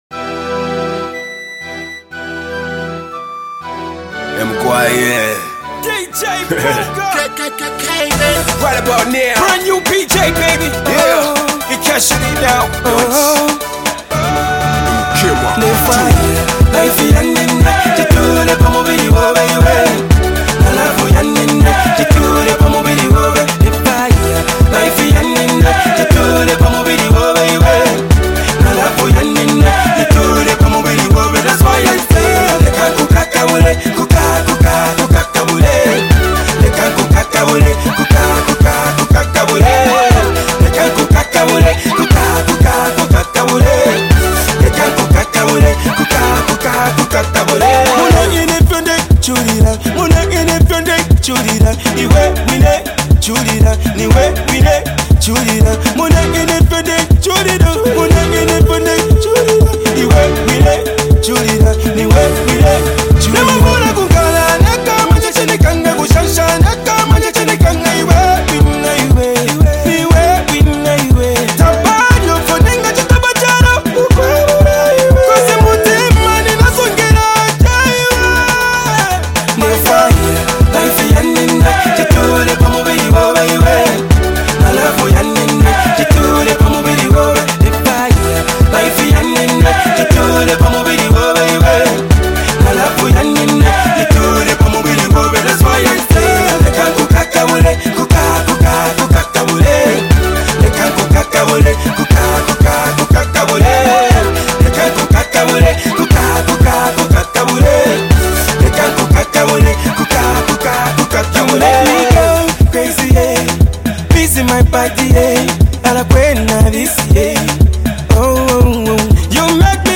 With his signature Afro-pop sound